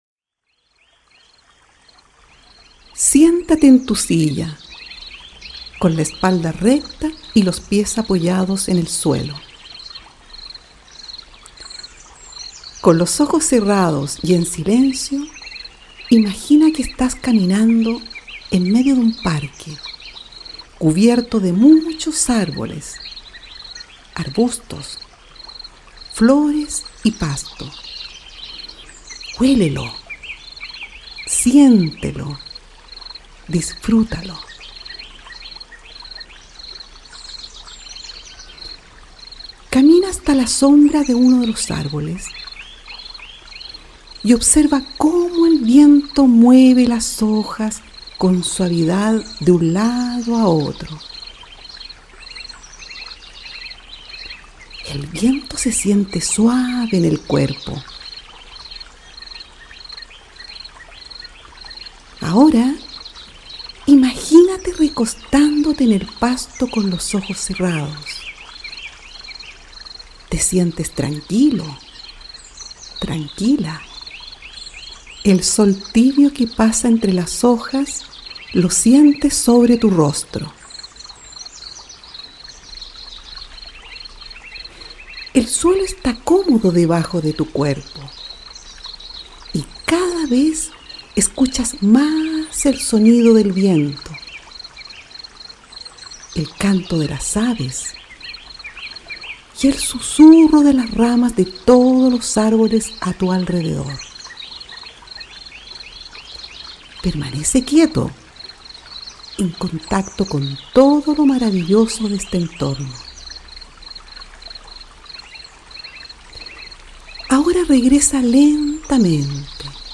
Ejercicio de relajación